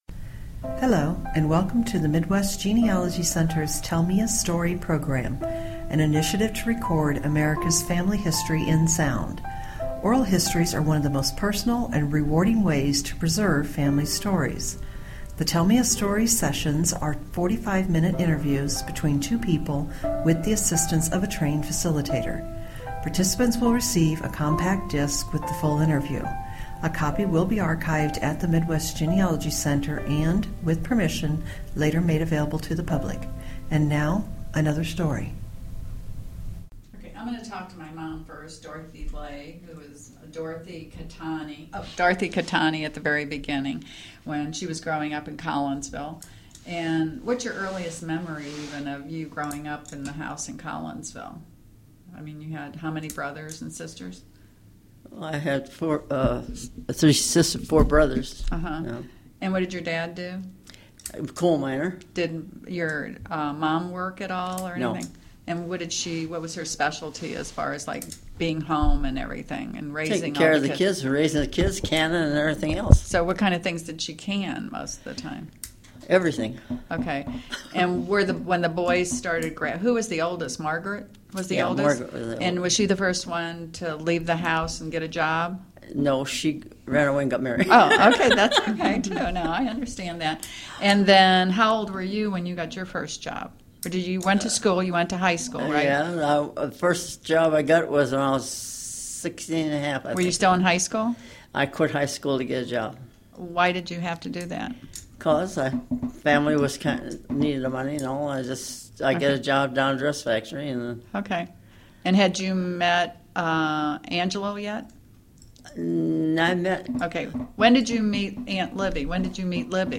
Oral history
Stereo